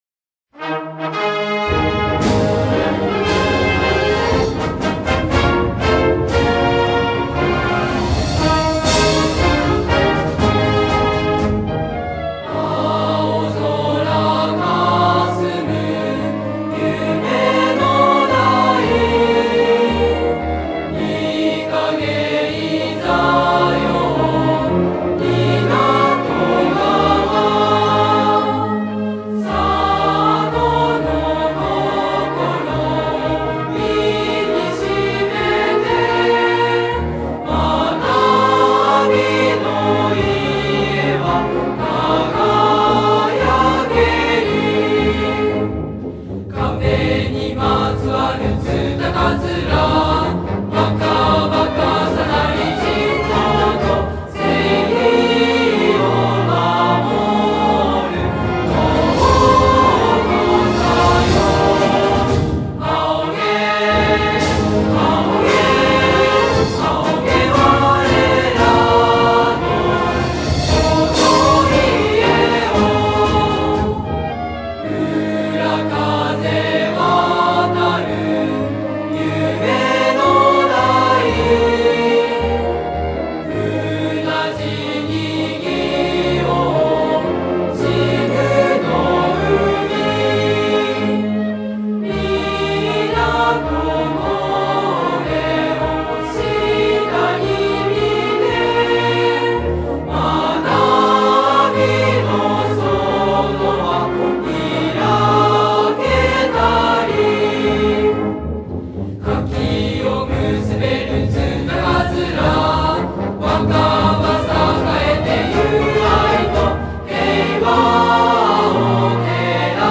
校歌演奏